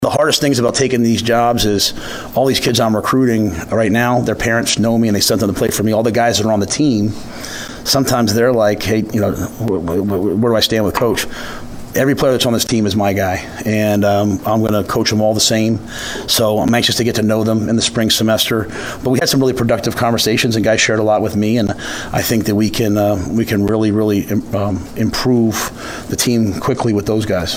Head Coach for Nebraska Football Matt Rhule provided a unique perspective and attitude towards the recruitment process and his current team in his first few weeks on campus at his National Signing Day press conference on Wednesday…